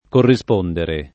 corrispondere [ korri S p 1 ndere ]